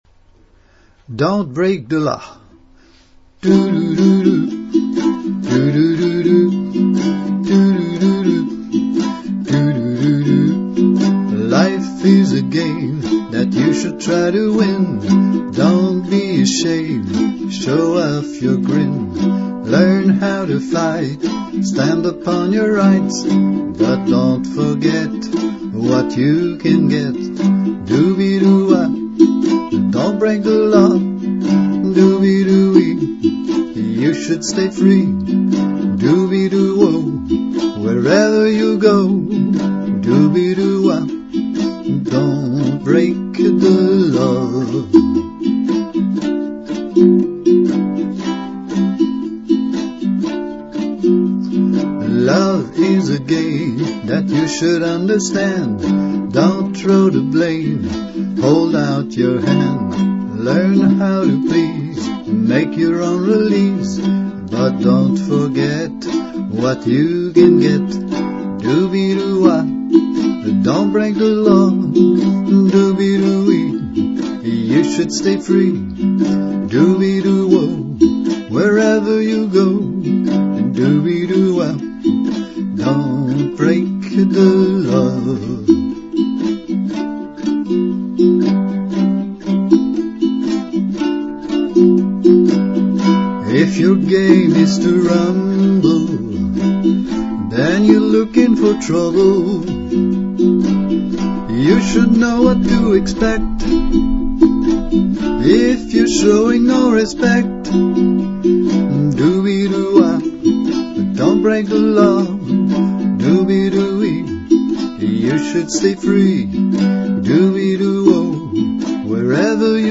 dontbreakthelaw_v22uke.mp3